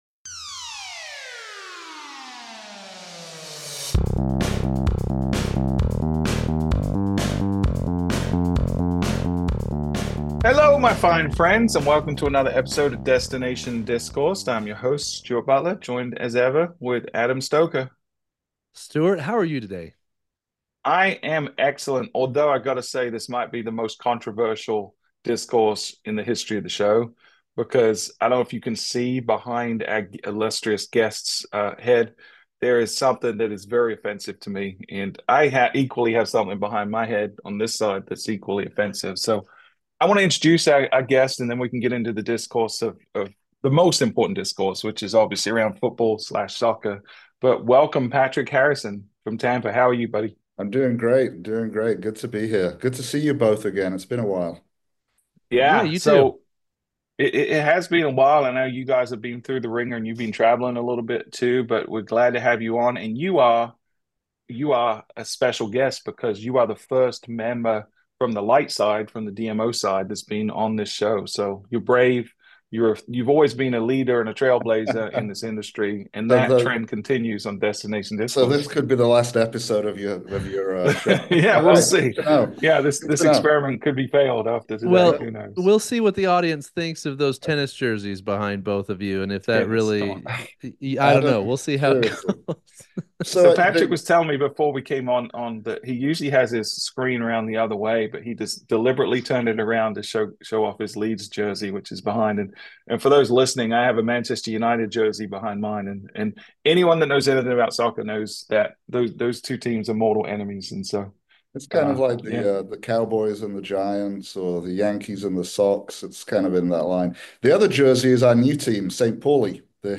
This engaging conversation explores how creative, scrappy, and grassroots campaigns can amplify destination marketing efforts—especially when budgets are tight or competition is fierce.